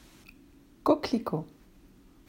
8. Coquelicot: Klatschmohn (kocklikoo)
Klar ist schon mal: Das C wird wie K ausgesprochen und das T am Ende ist stumm.